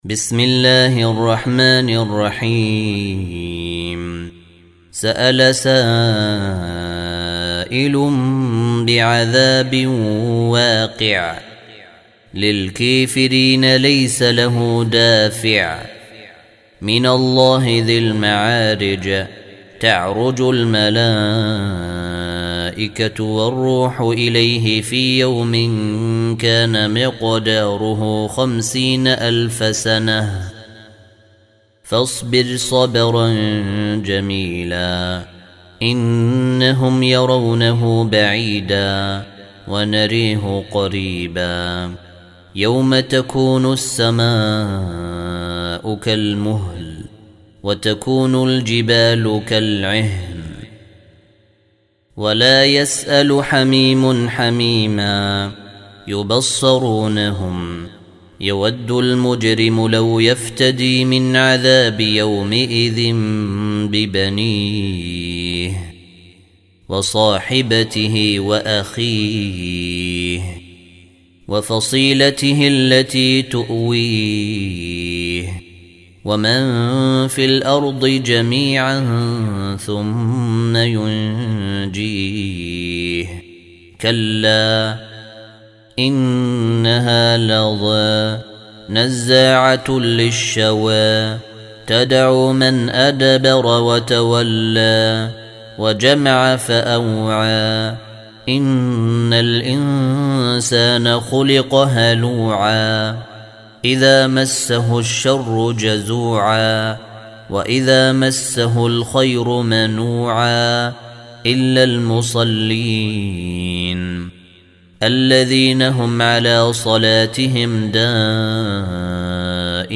Surah Repeating تكرار السورة Download Surah حمّل السورة Reciting Murattalah Audio for 70. Surah Al-Ma'�rij سورة المعارج N.B *Surah Includes Al-Basmalah Reciters Sequents تتابع التلاوات Reciters Repeats تكرار التلاوات